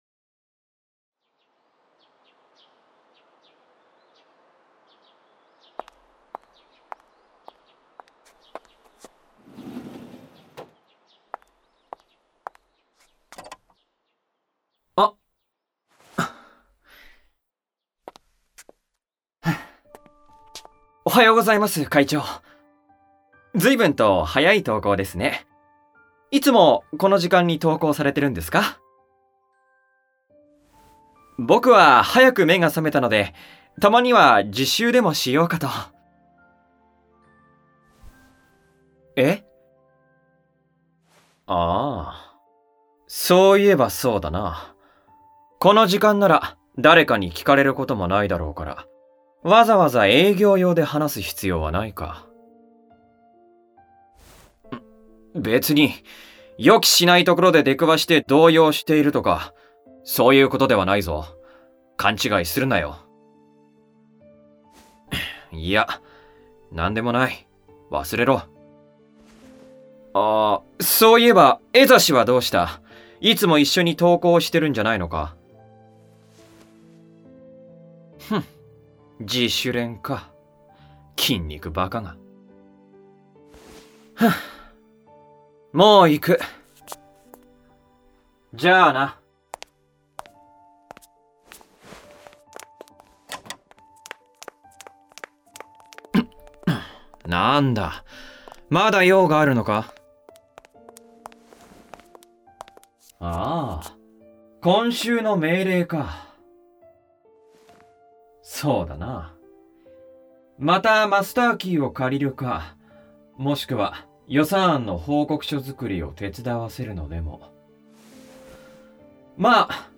●18歳以上推奨　●全編ダミーヘッドマイクにて収録